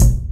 Announcment Kick (Add Reverb).wav